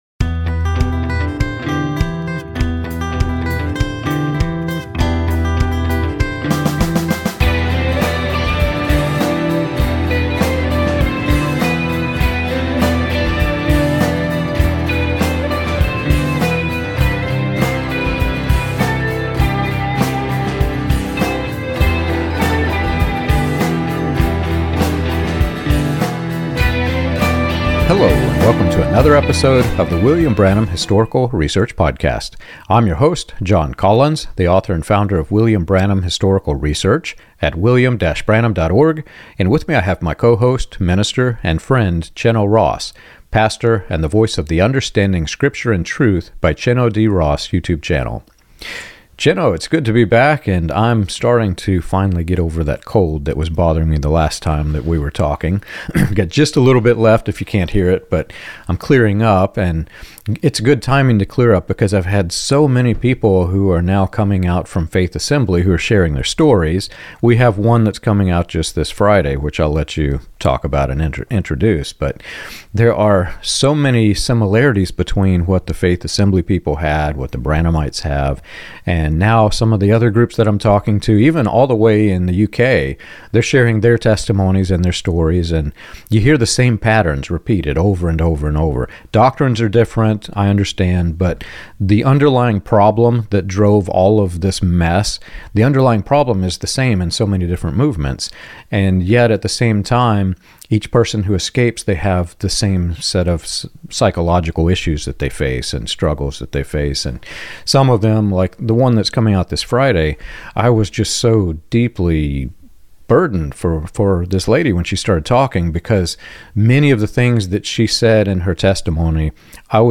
This conversation offers both language and hope for anyone trying to make sense of life after a controlling religious environment.